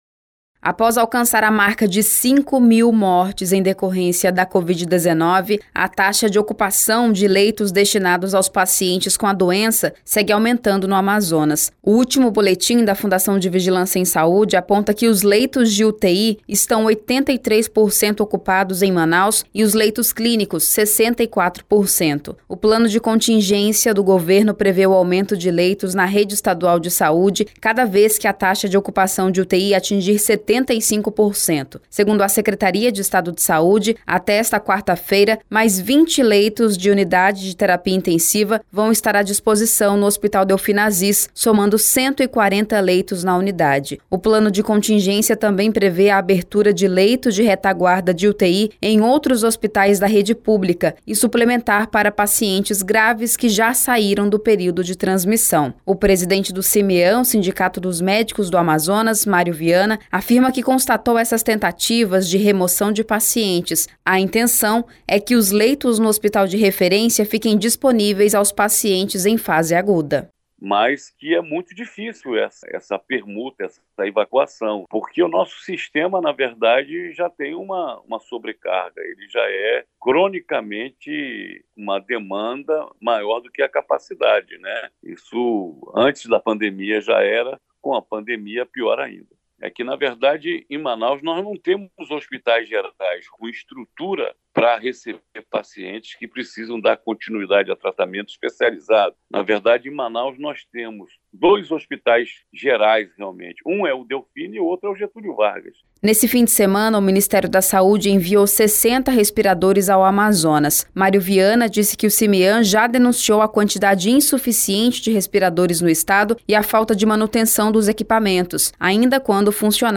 Segundo a Secretaria de Estado de Saúde, até esta quarta-feira (16), mais 20 leitos de Unidade de Terapia Intensiva vão estar à disposição no Hospital Delphina Aziz, somando 140 leitos na unidade. Confira a reportagem: